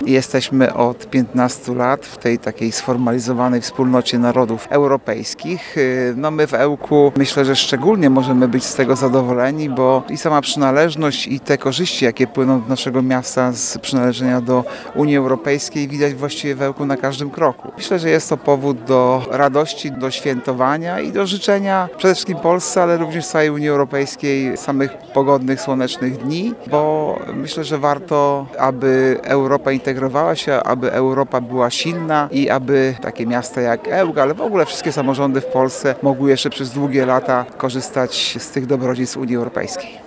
– To ważna rocznica dla nas wszystkich, Polaków, ełczan – mówi zastępca prezydenta miasta, Artur Urbański.